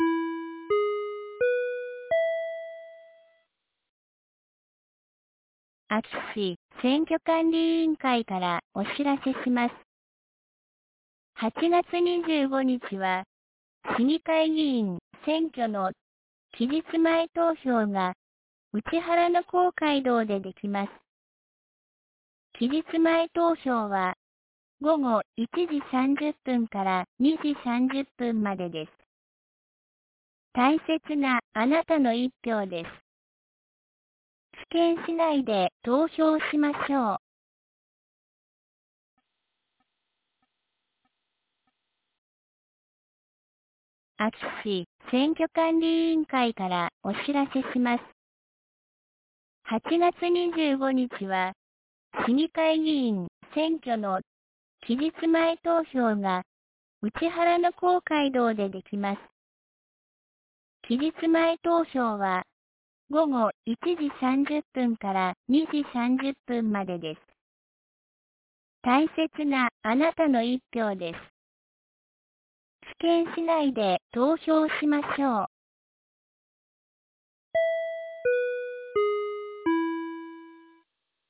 2022年08月22日 12時11分に、安芸市より井ノ口へ放送がありました。